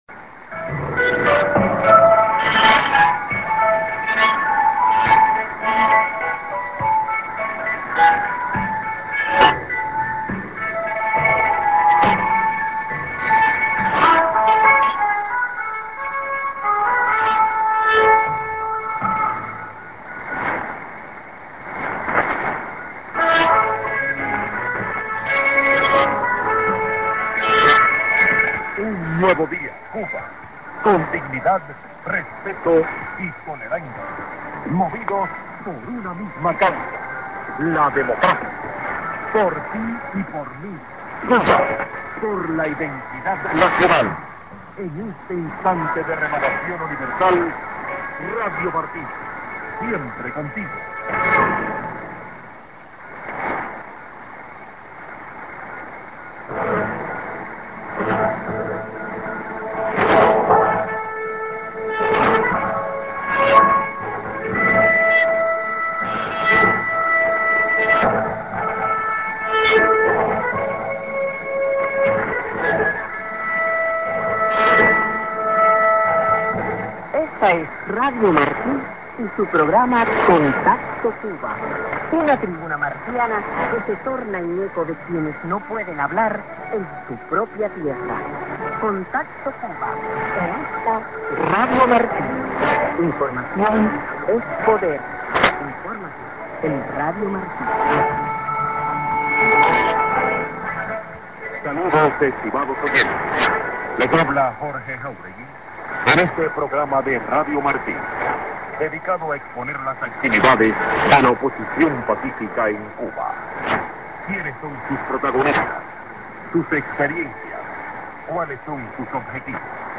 Span. IS(ST)->ID(man)->01'20":ID(women)->